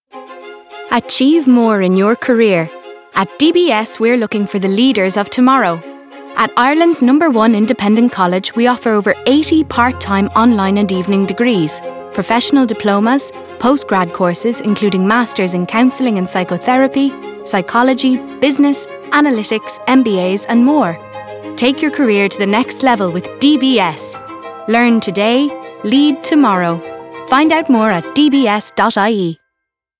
DBS-Radio-3-30-sec-PGPT.wav